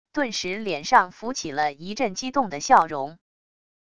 顿时脸上浮起了一阵激动的笑容wav音频生成系统WAV Audio Player